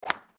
Golf_Hit_Ball.ogg